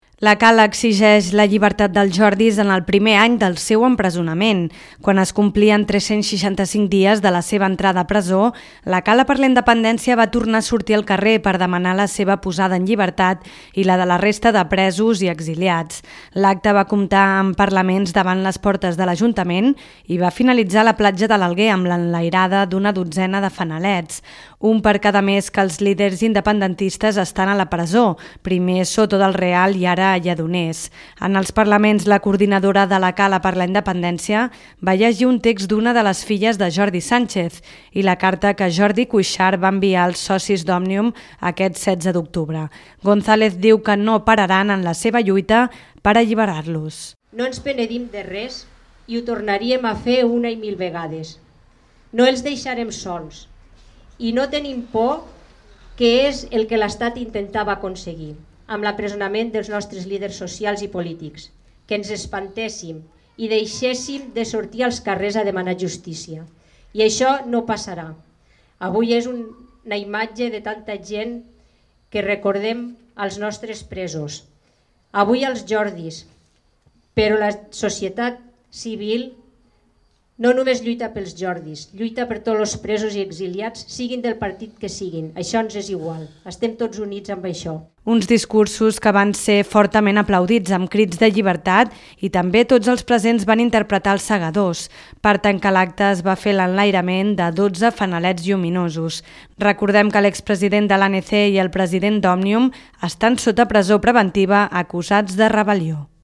L’acte va comptar amb parlaments davant les portes de l’Ajuntament, i va finalitzar a la platja de l’Alguer amb l’enlairada d’una dotzena de fanalets.